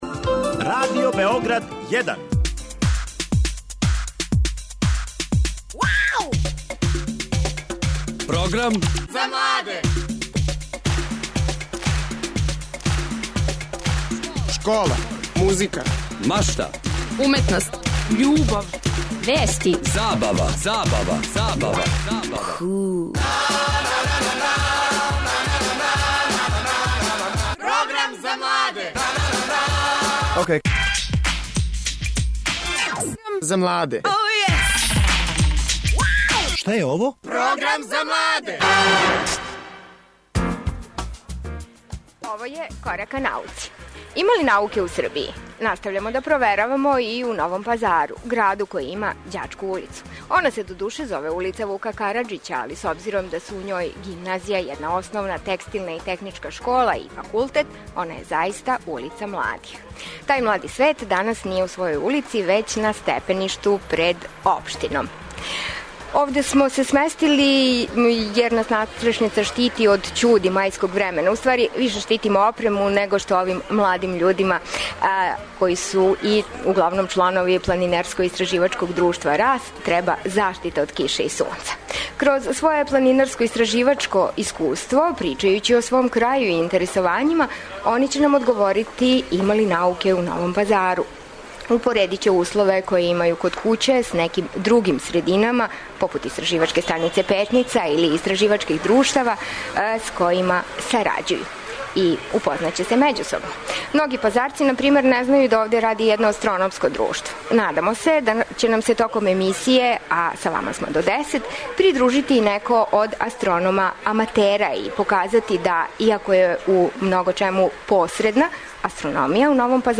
Разговарамо са члановима Планинарско-истраживачког друштва "Рас", младим људима који се занимају за природне науке. Емисија се реализује са Трга у Новом Пазару.